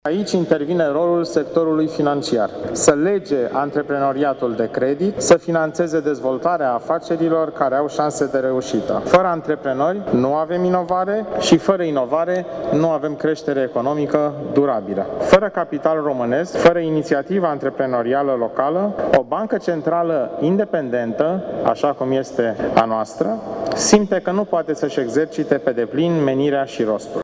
Începând de mâine, timp de două săptămâni, puteţi vizita expoziţia „100 de ani de antreprenoriat în România”, la Muzeul Băncii Naţionale a României din Bucureşti. Ieri, la vernisaj, viceguvernatorul Băncii Naţionale, Liviu Voinea a subliniat importanţa antreprenorilor şi a capitalului autohton pentru economia românescă.